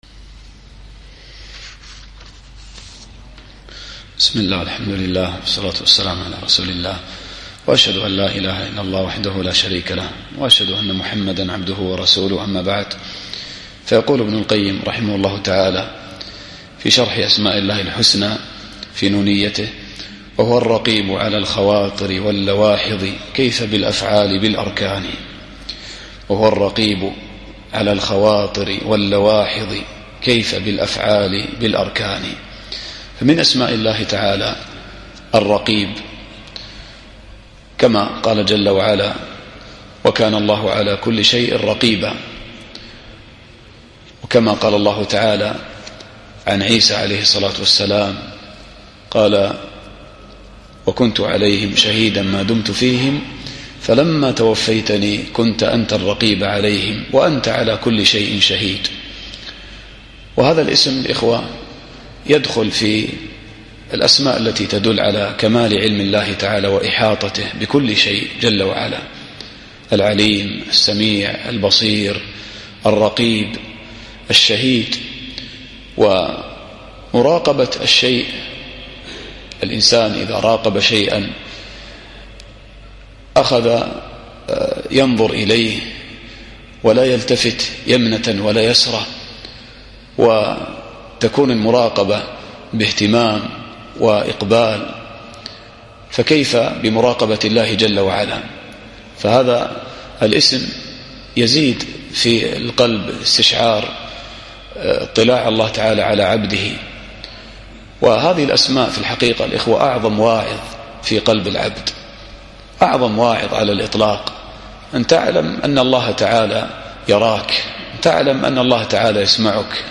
الدرس الخامس والعشرون